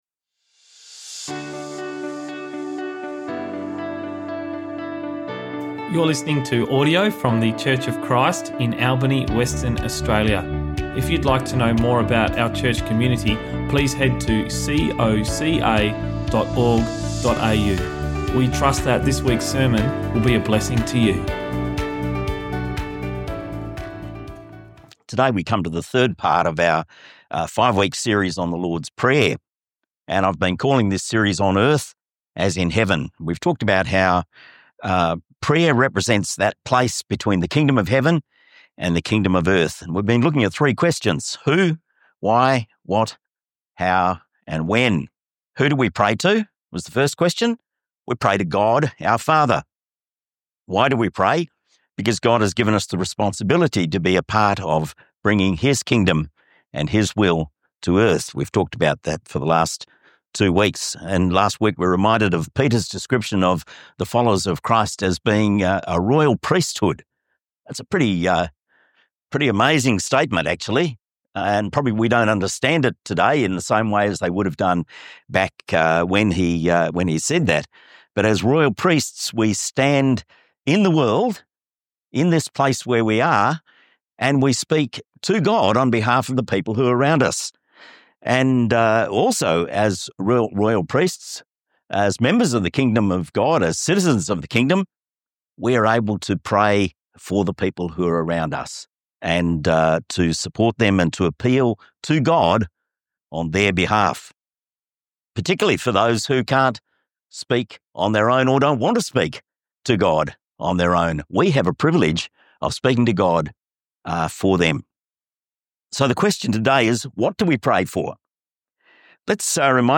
Sermons | Church of Christ Albany